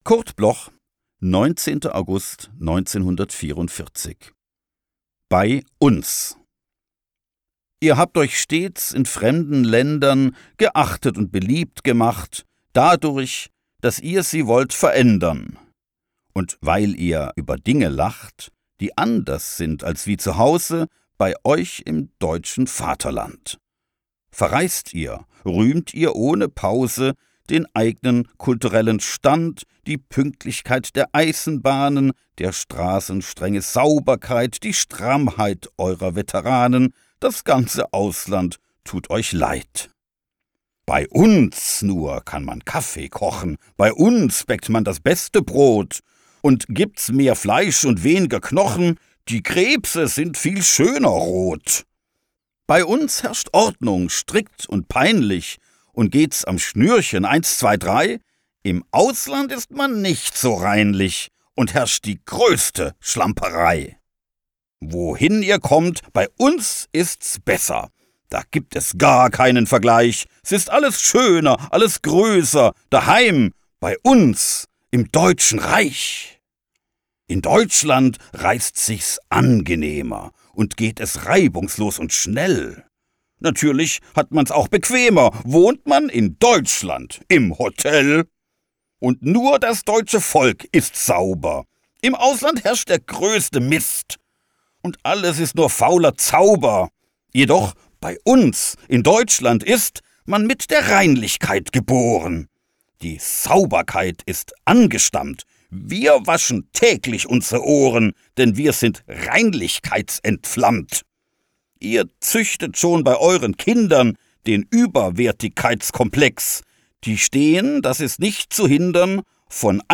aufgenommen im Tonstudio Kristen & Schmidt, Wiesbaden